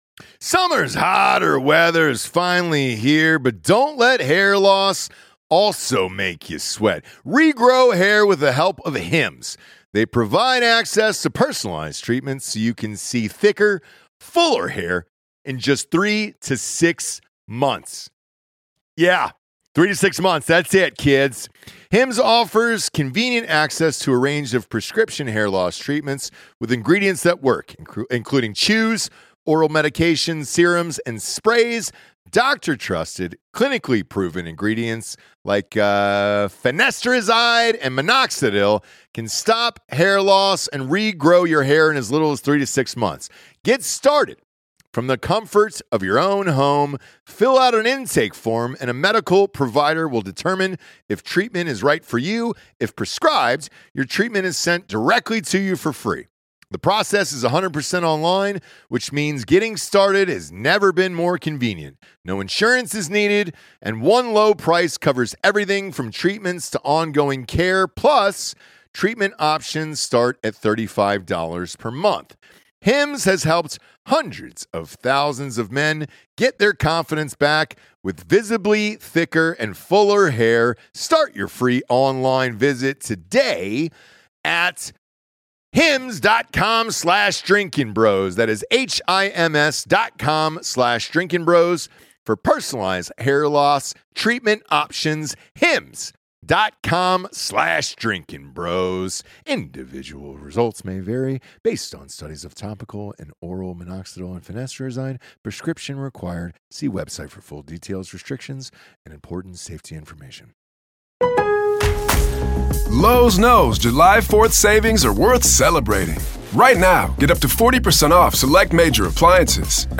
comedian and podcast legend Adam Carolla